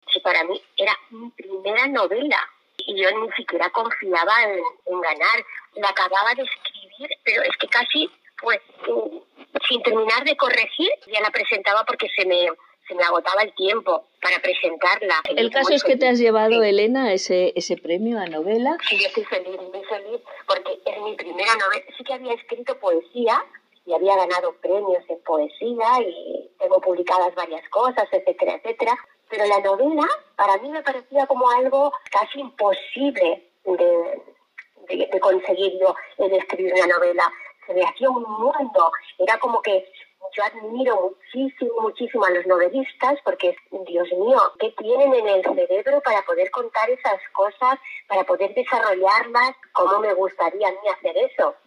con reflexiva calma formato MP3 audio(0,99 MB).